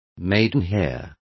Complete with pronunciation of the translation of maidenhairs.